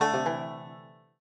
banjo_daece.ogg